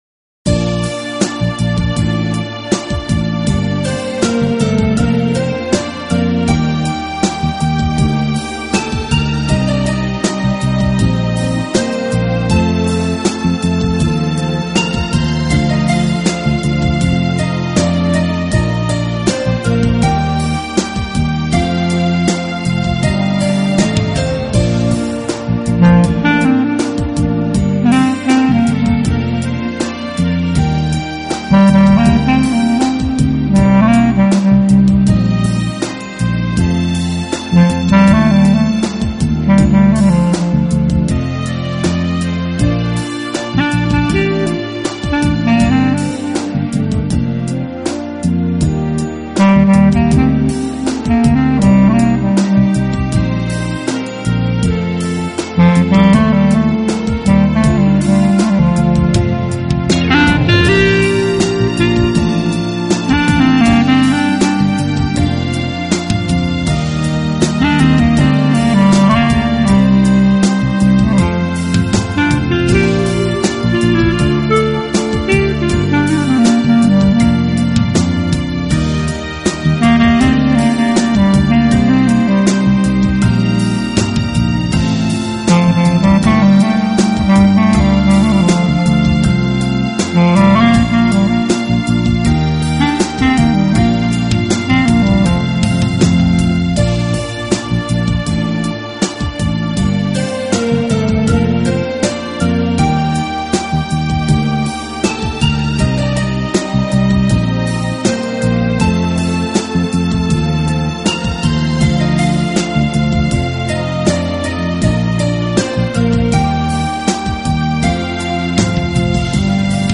Genero/Genre: Smoth Jazz/Orchestral Pop
用萨克管演奏情调爵士乐，上世纪六十年代开始很走红，到上个世纪七十年代达到顶峰，